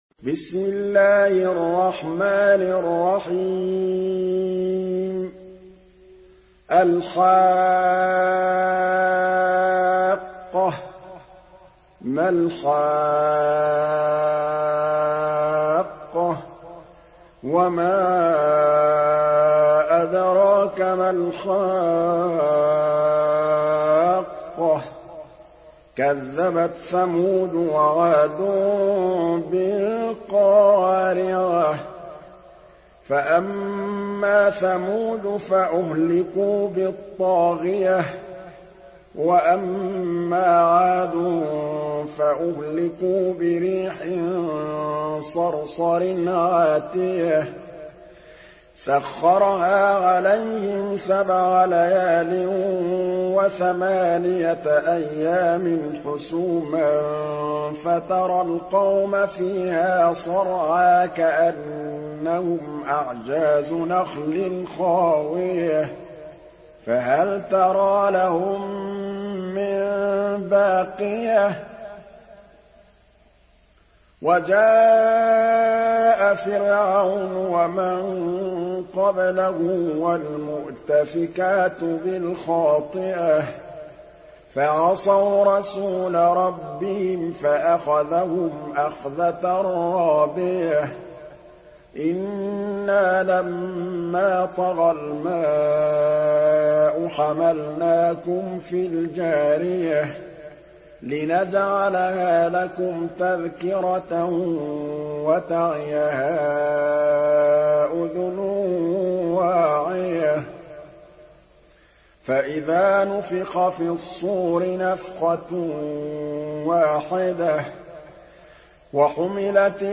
Surah আল-হাক্কাহ্ MP3 by Muhammad Mahmood Al Tablawi in Hafs An Asim narration.
Murattal Hafs An Asim